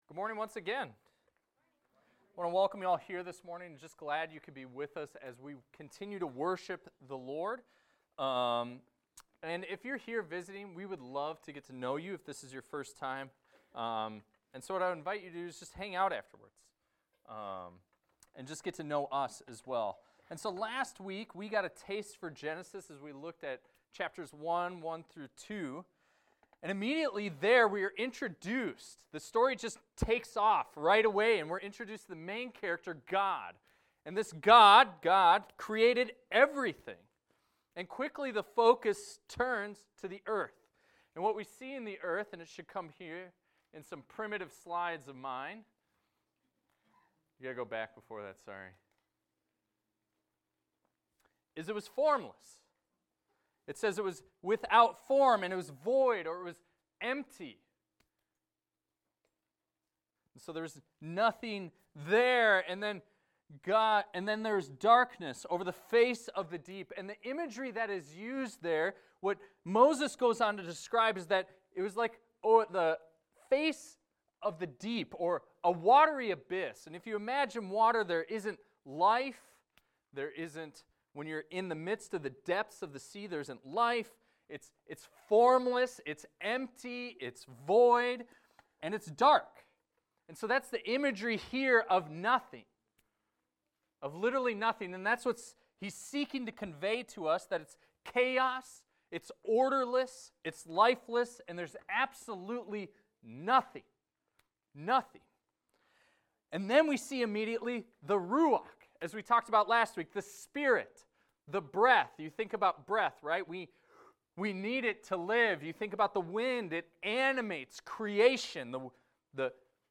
This is a recording of a sermon titled, "The One True God."